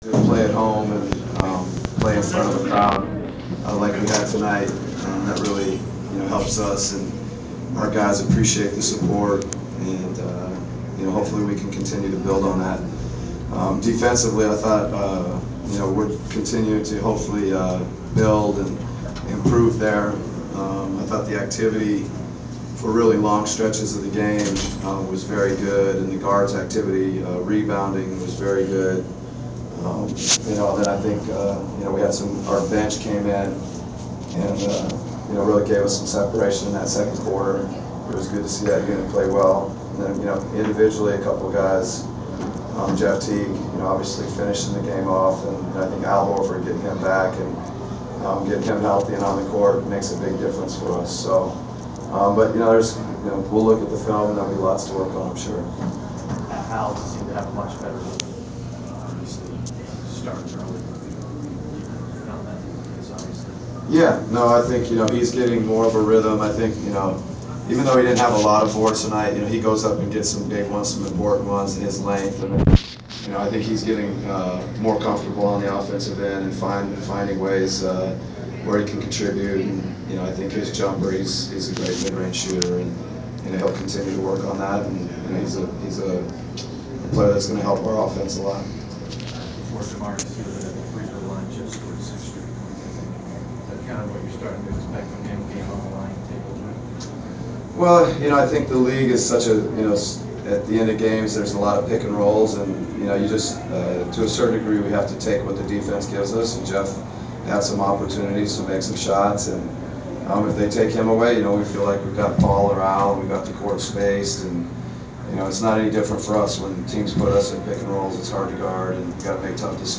Postgame press conference with Atlanta Hawks’ coach Mike Budenholzer 11/1/14